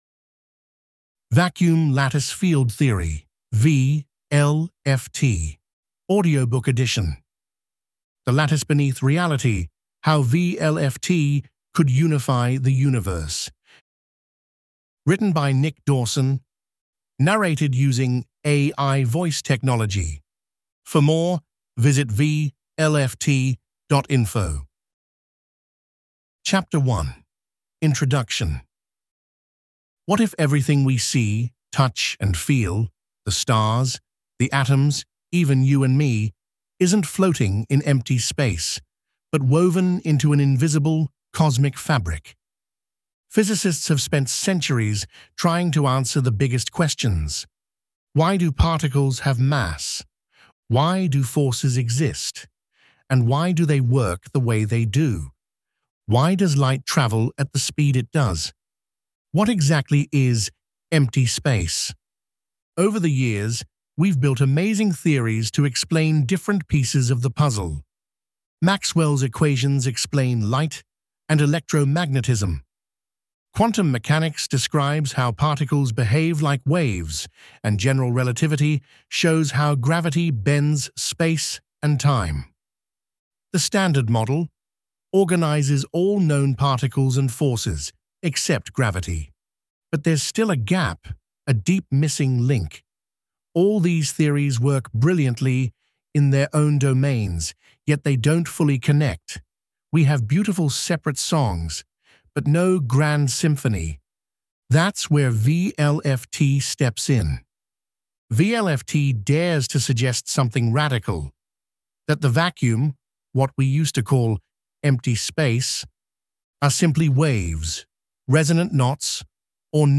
Reader-Friendly Audiobook
A narrated edition of our non-technical summary